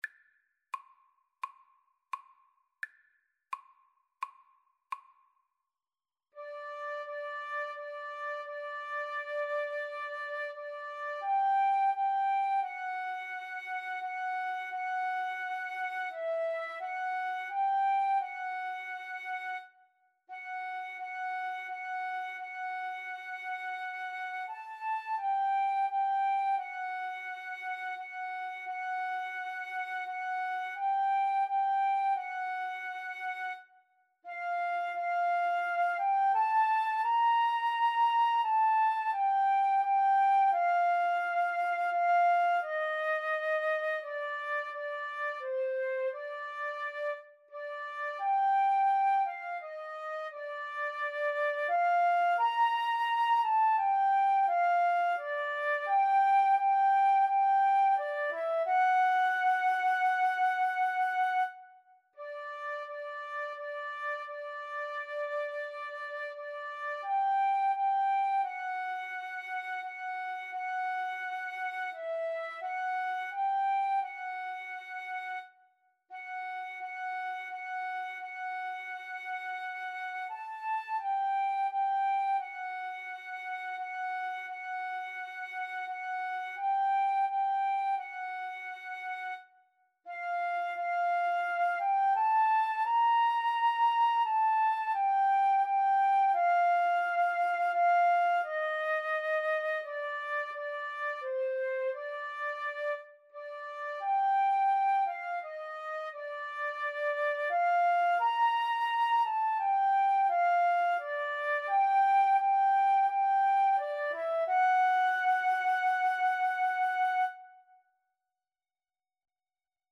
Andante =c.86